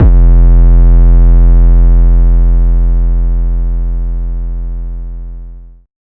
REDD 808 (18).wav